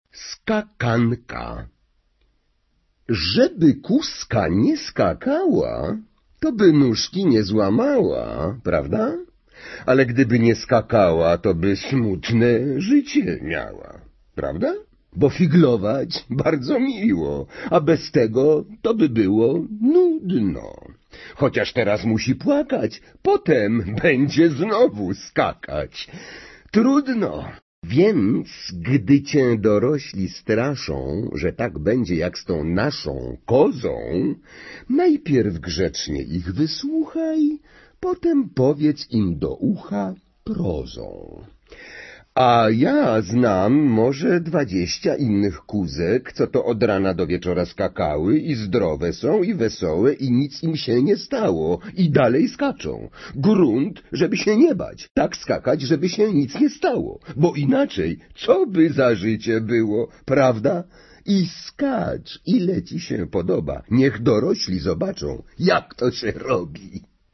Читать мы будем, конечно же, опять в оригинале, и снова нам в этом поможет голос Пётра Фрончевского.